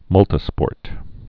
(mŭltĭ-spôrt) also mul·ti·sports (-spôrts)